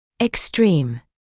[ɪks’triːm][икс’триːм]крайний; экстремальный; чрезвычайный; имеющий крайние значения